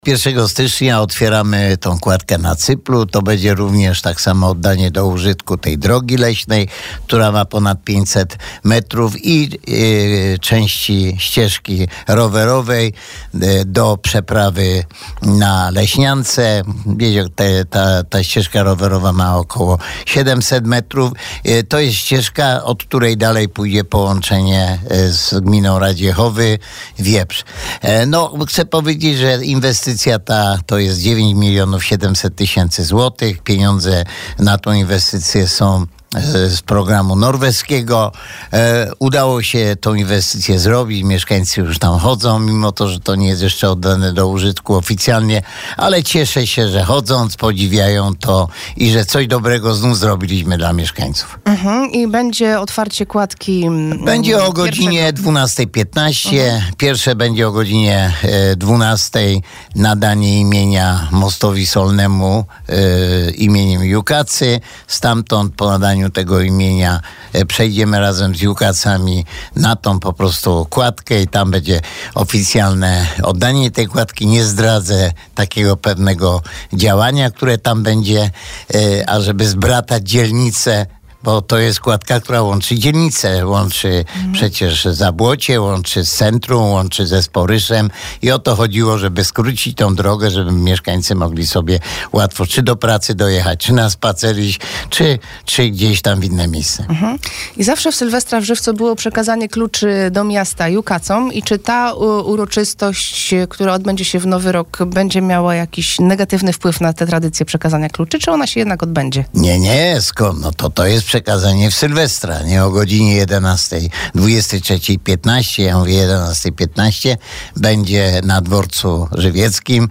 A tak o nowej kładce, ścieżce i sylwestrowo-noworocznych uroczystościach mówił na naszej antenie burmistrz Żywca, Antoni Szlagor.